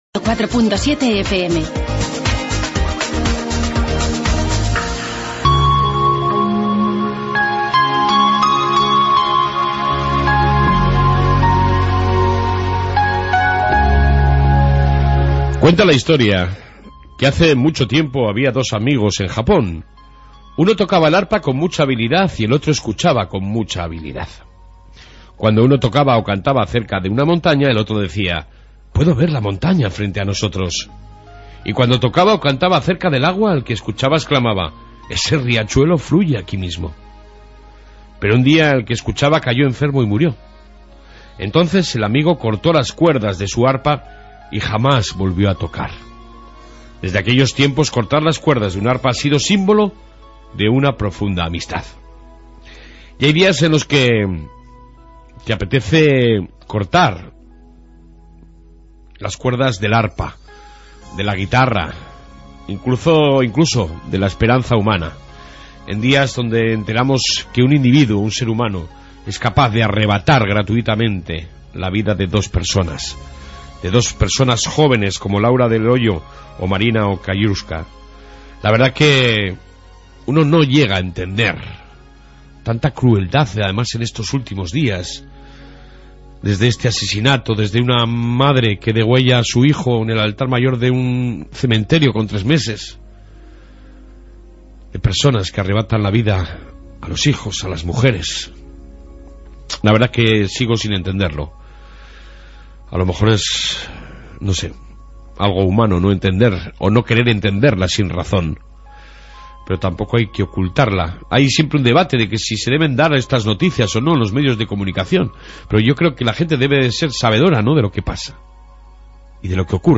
AUDIO: Reflexión matutina, Informe Polícia Municipal y entrevista al alcalde de Murchante Tomas Aguado sobre las fiestas y algo mas...